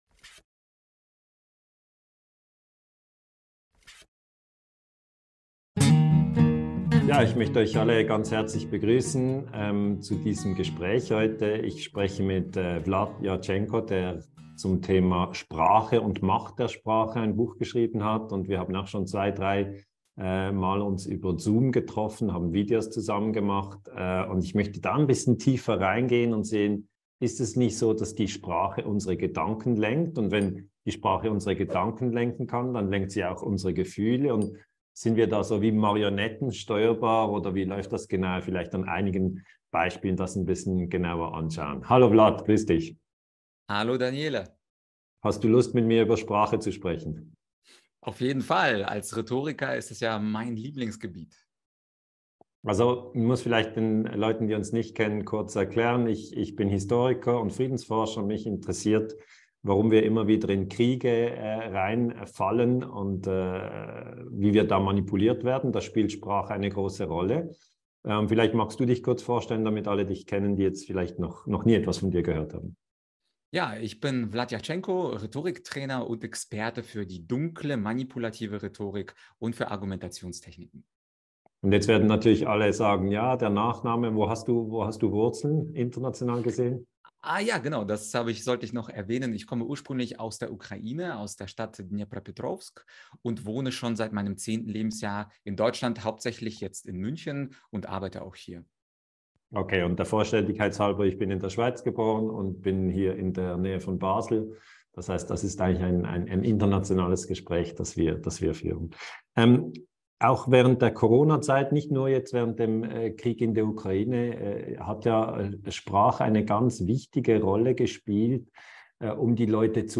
Daniele Ganser zum Thema Dunkle Rhetorik in der Corona-Politik. Im Interview